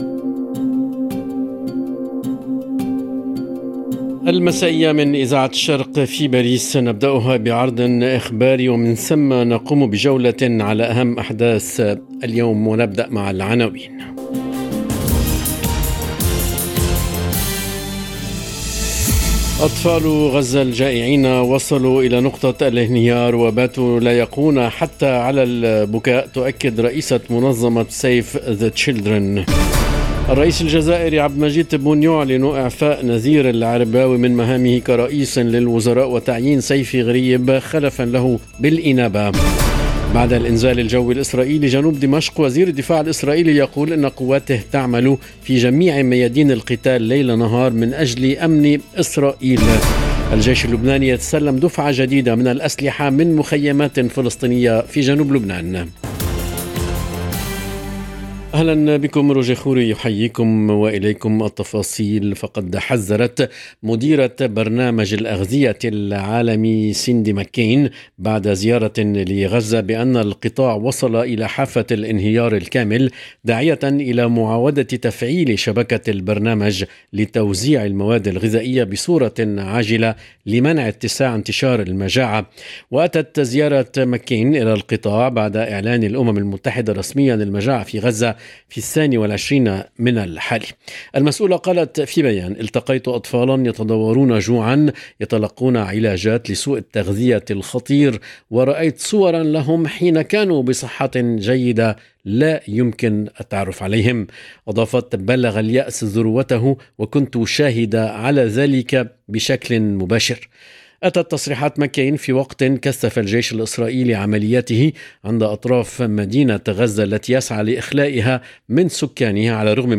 نشرة أخبار المساء: صرخات غزة الجائعة، ارتدادات السياسة في الجزائر، ورسائل عسكرية من دمشق إلى جنوب لبنان - Radio ORIENT، إذاعة الشرق من باريس